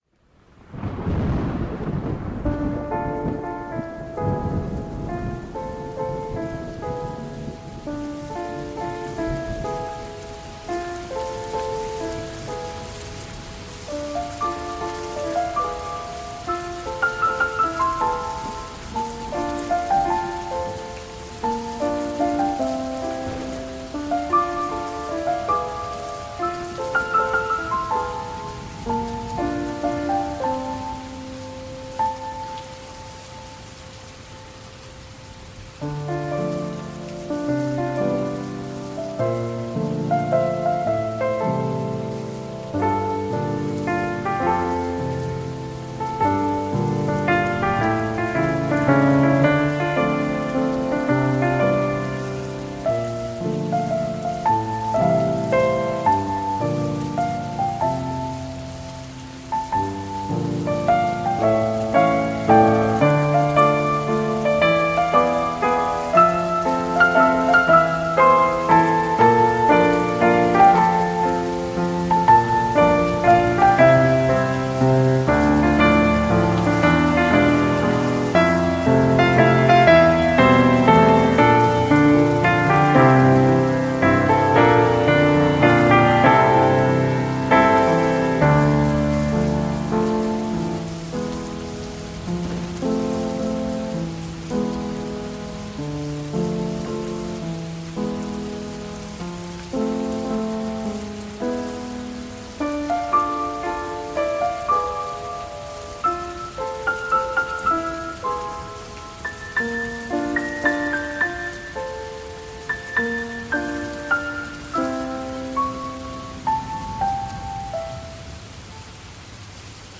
A piano cover